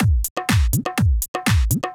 123 BPM Beat Loops Download